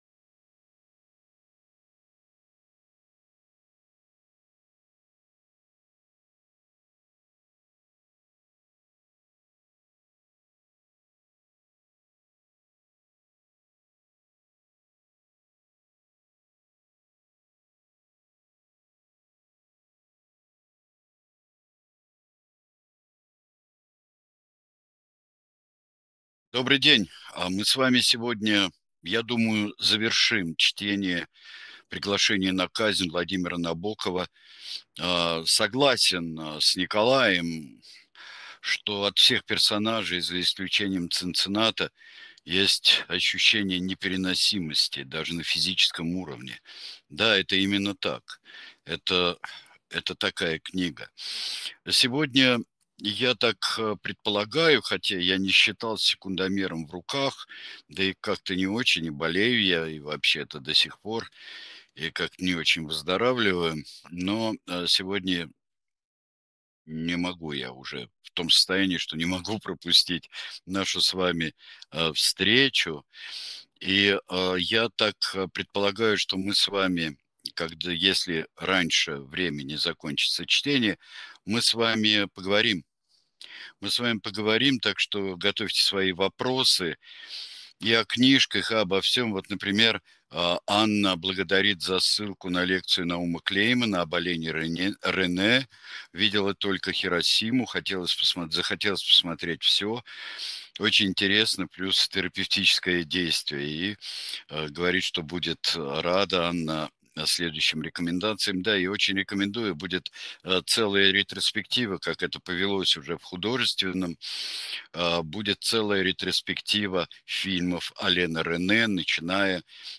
Читает Сергей Бунтман